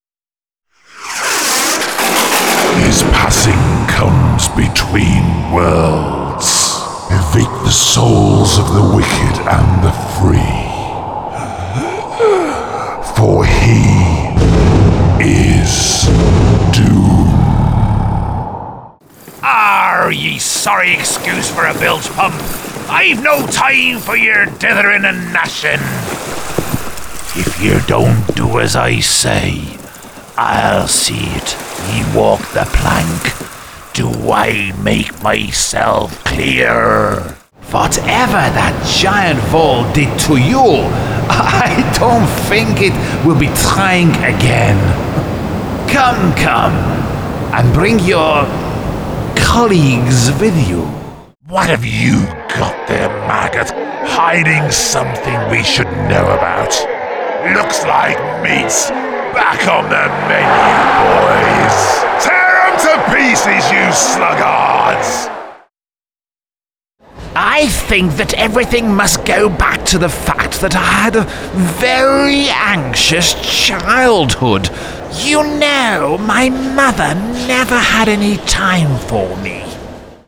Voiceovers
✔ His voice range is from whisper through to a very loud yell!
Games